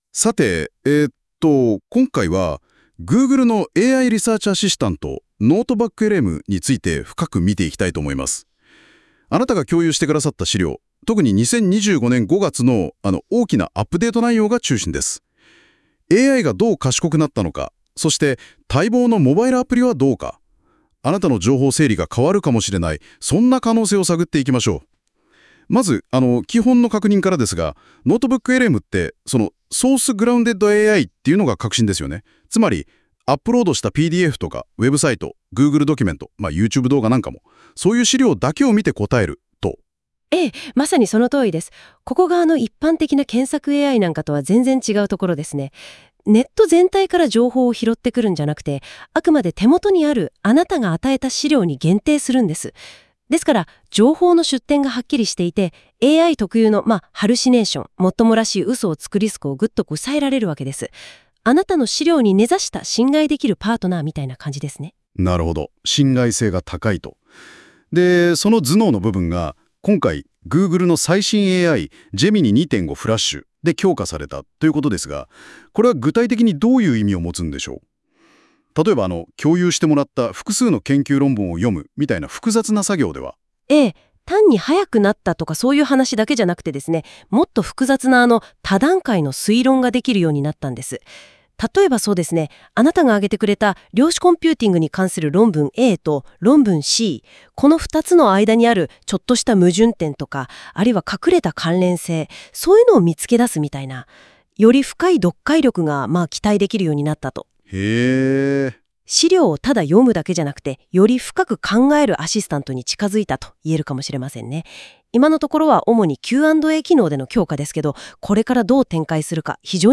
• 「多言語オーディオ機能」で日本語ポッドキャスト風の要約音声を自動生成
2. 読み込んだテキストをもとにAIが自動的に音声コンテンツを生成
3. インタラクティブなポッドキャスト形式で内容を解説
特に日本語での音声生成は非常に自然な話し方で、まるで人間のナレーターが話しているかのような質の高さです。